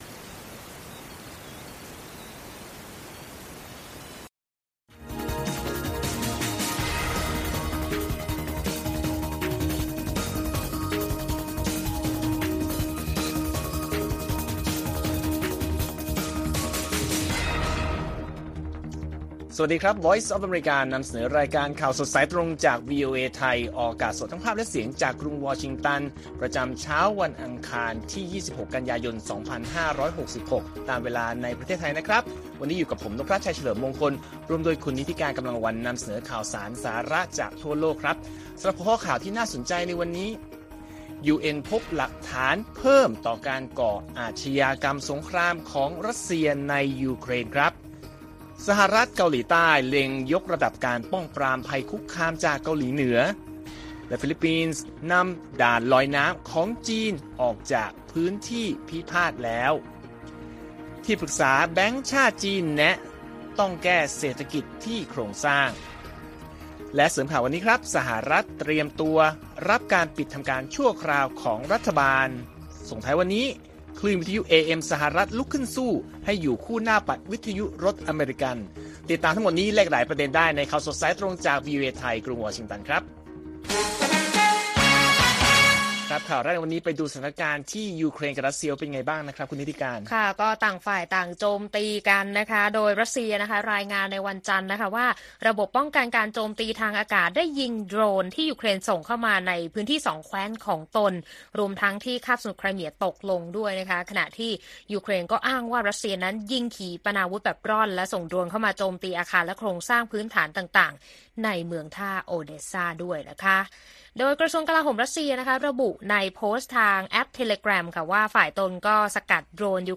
ข่าวสดสายตรงจากวีโอเอ ไทย อังคาร 26 กันยายน 2566